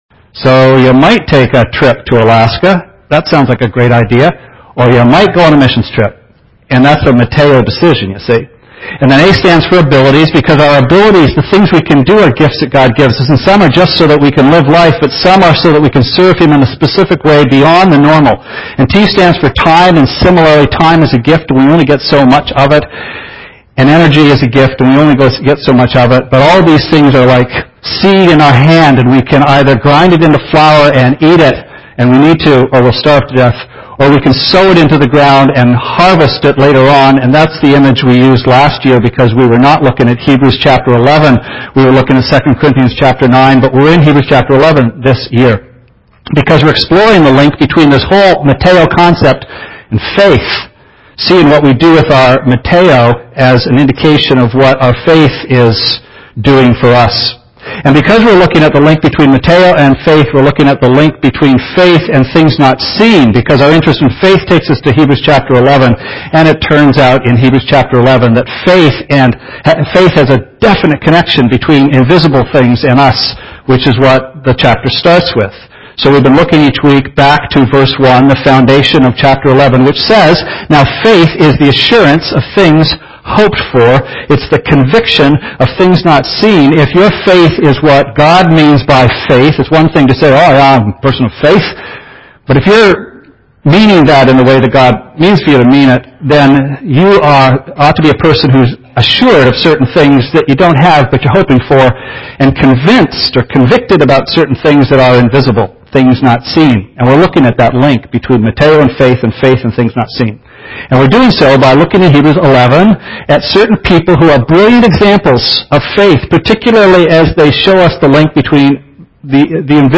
Sermon Archives - West London Alliance Church
The 5th in this series focusses on Abraham's wife, Sarah, and the opportunity that God enabled her to take, an opportunity of a very distinct sort, "MATEO-wise"! [Please note and accept our apologies: the first few minutes of the sermon were not recorded.)